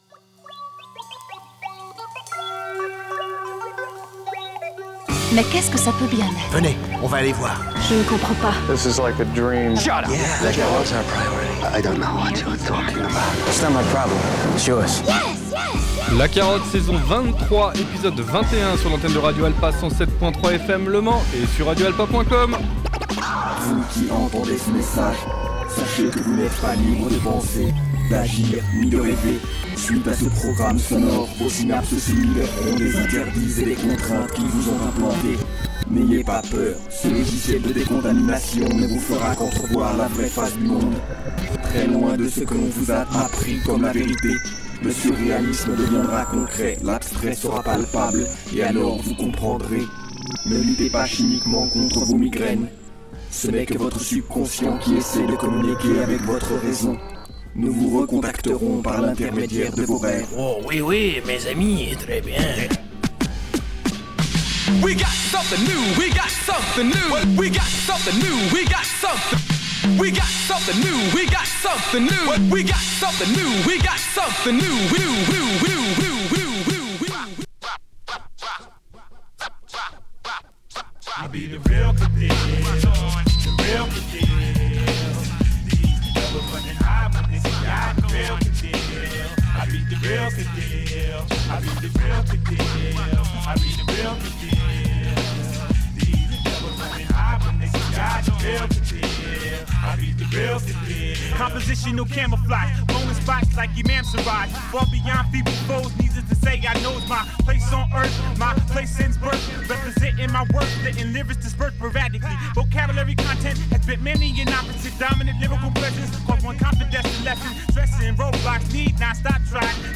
où le hip hop s’acoquine avec le jazz